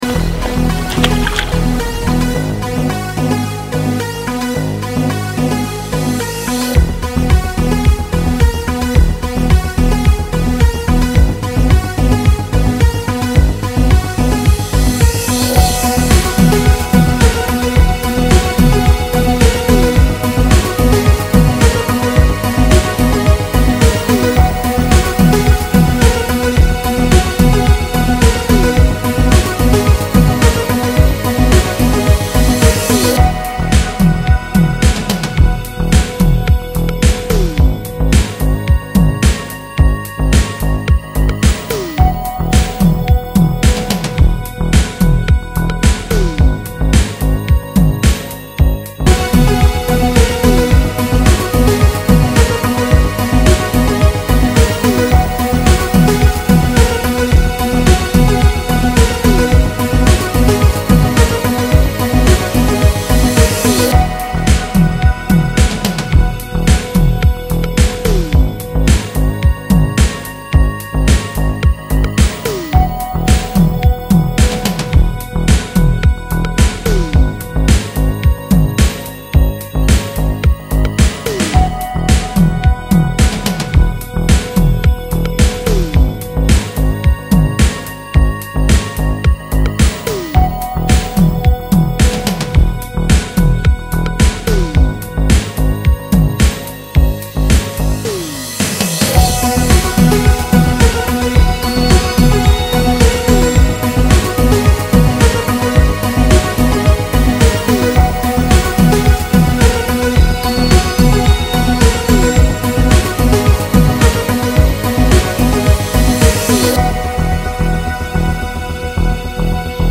Пожалуйста подскажите название электронной мелодии с 31:34.